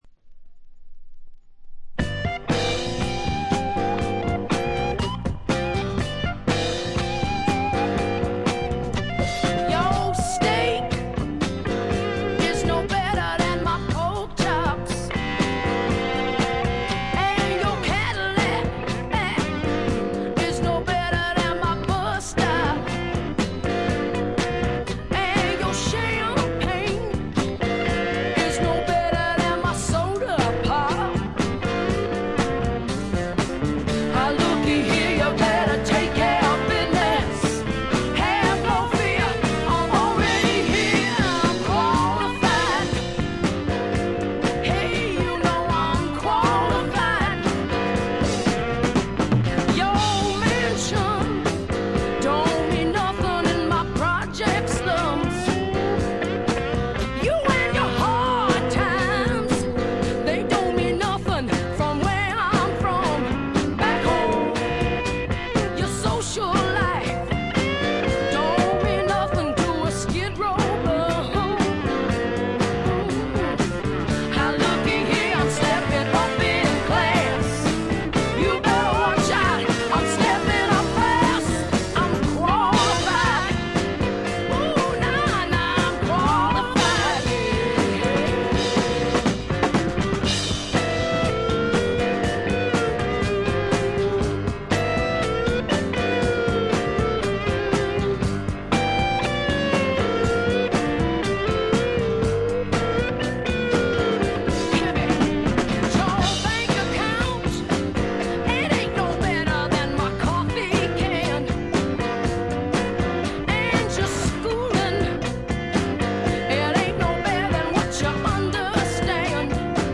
ほとんどノイズ感無し。
ニューヨーク録音、東海岸スワンプの代表作です。
いかにもイーストコーストらしい機知に富んだスワンプアルバムです。
試聴曲は現品からの取り込み音源です。